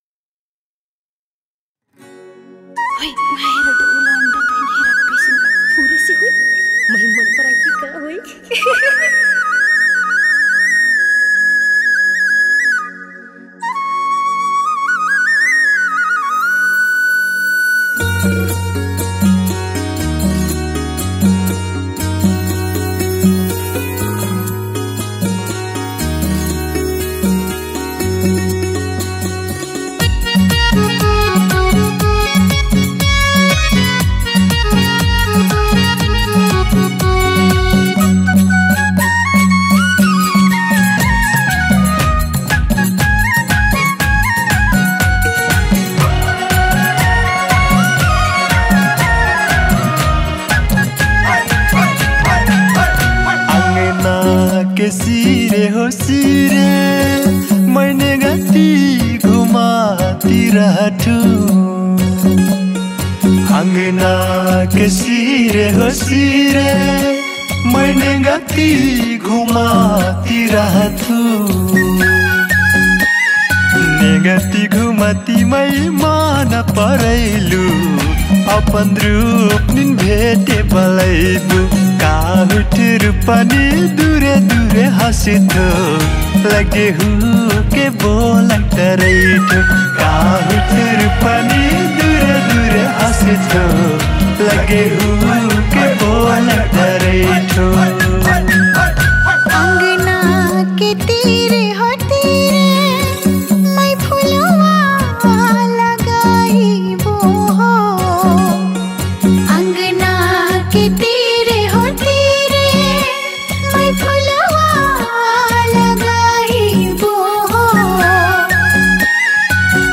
Tharu Culture Song